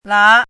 “剌”读音
剌字注音：ㄌㄚˋ/ㄌㄚˊ
国际音标：lɑ˥˧;/lɑ˧˥
lá.mp3